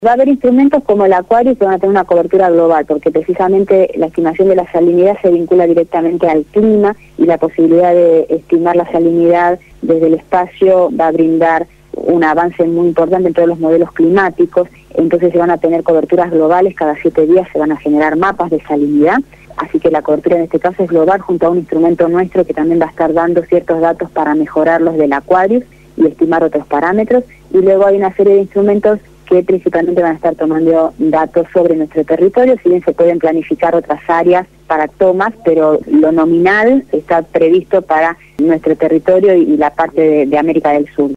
habló en Radio Gráfica FM 89.3 la mañana del viernes, antes del lanzamiento del satélite